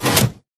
Sound / Minecraft / tile / piston / in.ogg